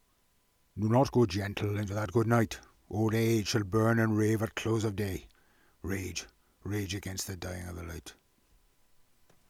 South Wales Poetry